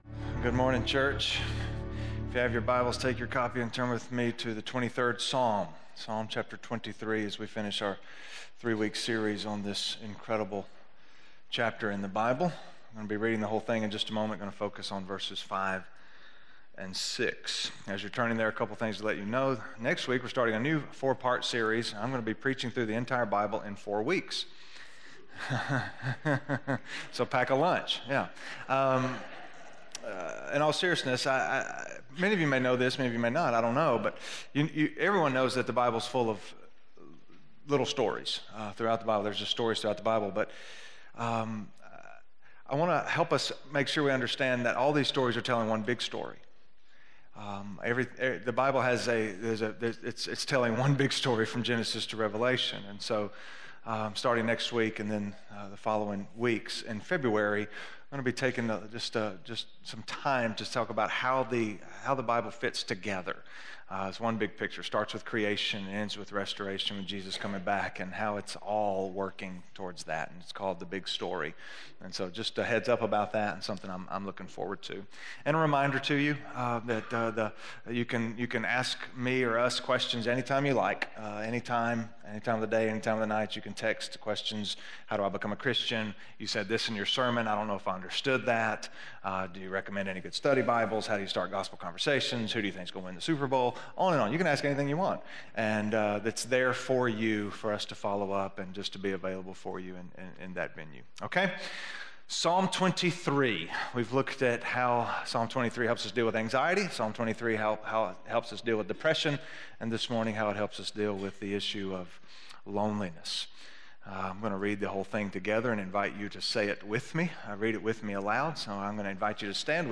Dealing With Loneliness - Sermon - West Franklin